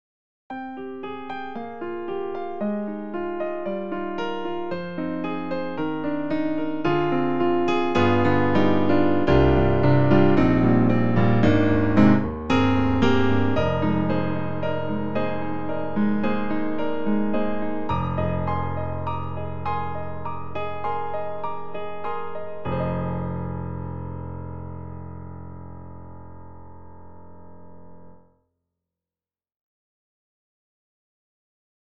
特集：徹底比較！DTM音源ピアノ音色聴き比べ - S-studio2
Studio
e-instruments_Session Keys Grand Y_Studio.mp3